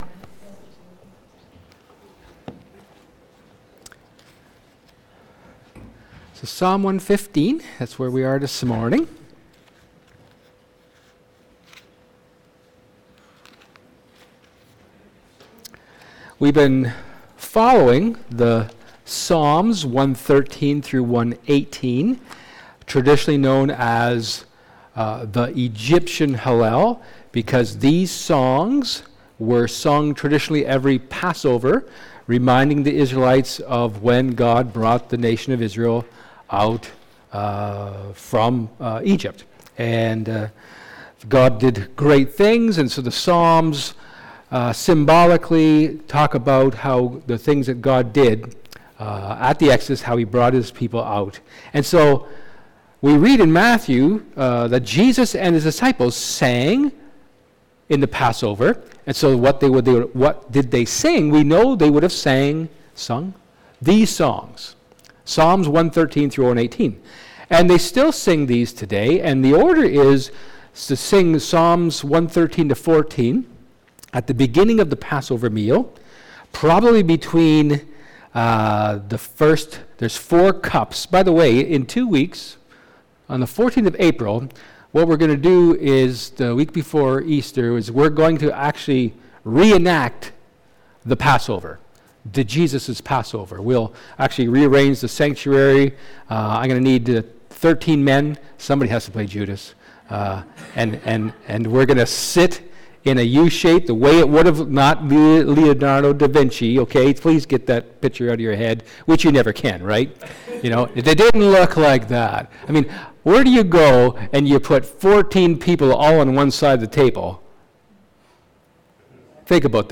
Micah 2 Service Type: Sermon